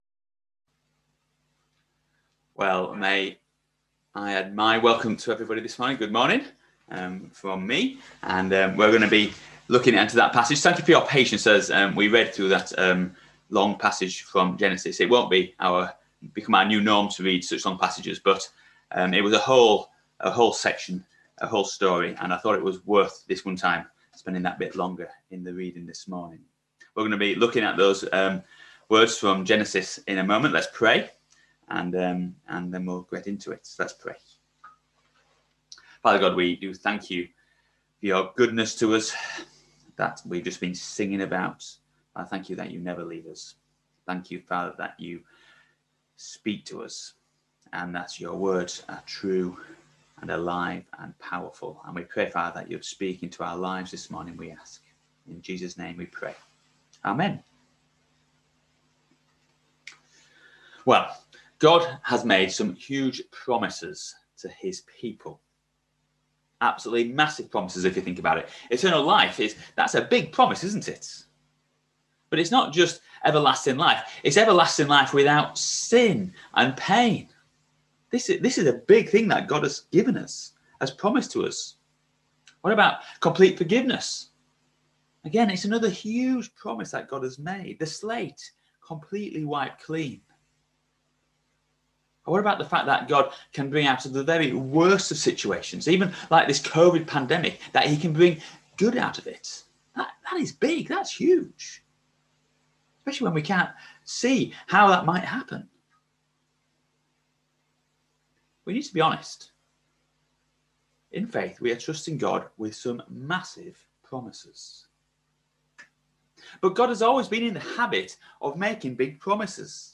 Genesis 26v34-28v9 Service Type: Sunday Morning Service Topics